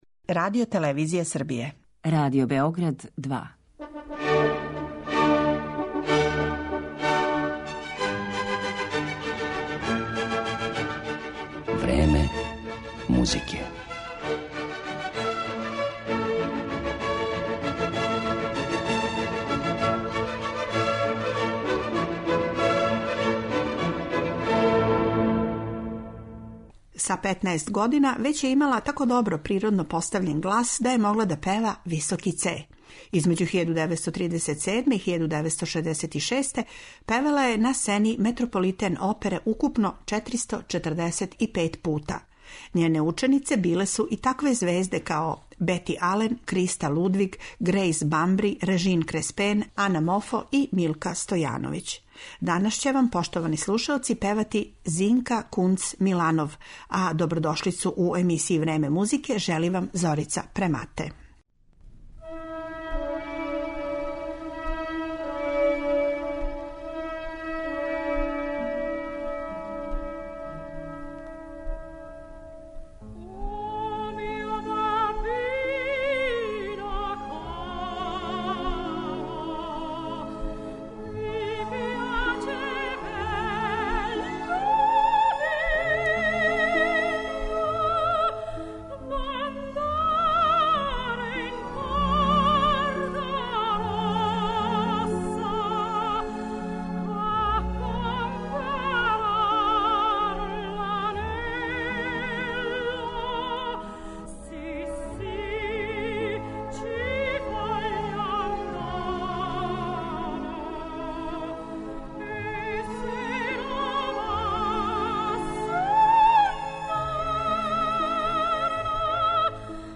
Драмски сопран Зинка Кунц-Миланов била је једна од највећих звезда Метрополитена средином прошлог века.